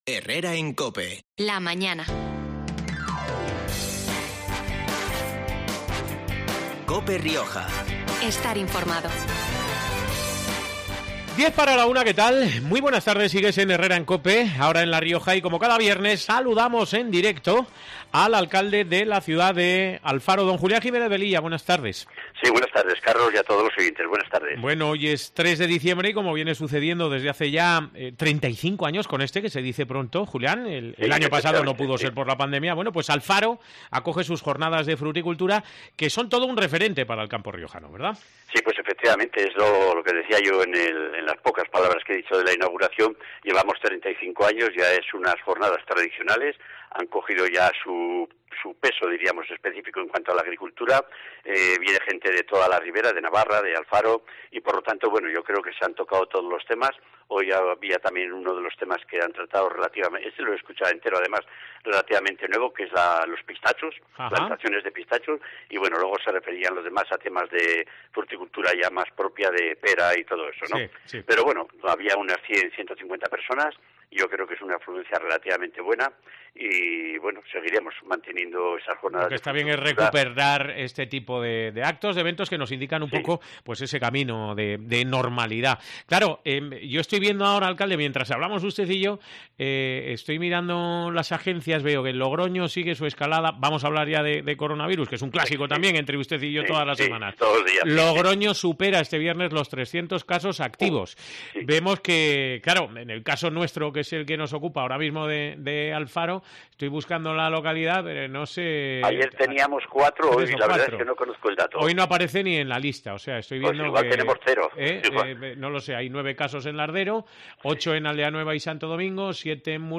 El alcalde, Julián Jiménez Velilla , agradecía hoy en los micrófonos de COPE Rioja el trabajo realizado por los voluntarios encargados de dar forma a ese gran Belén.